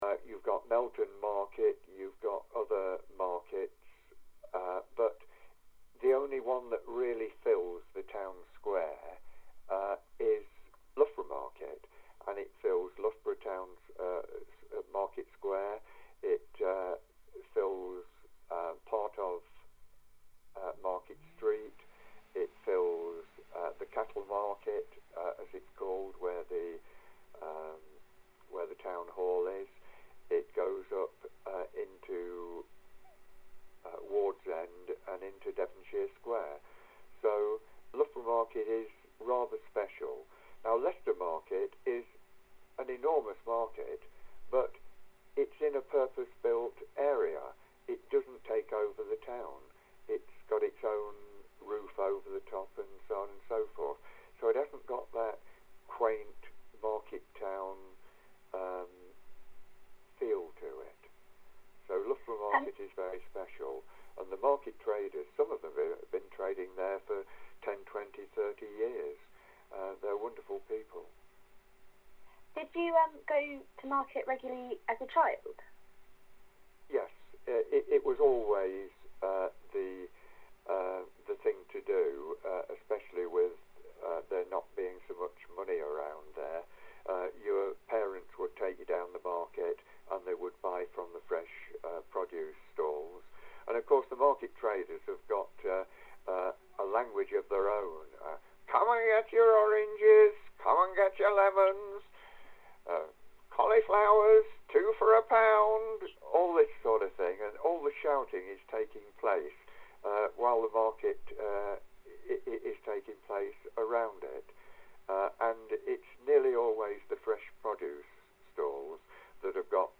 Oral History Interviews – Market
Listen below to audio clips taken from interviews conducted by our volunteers with the community reflecting on 800 years of Loughborough’s market.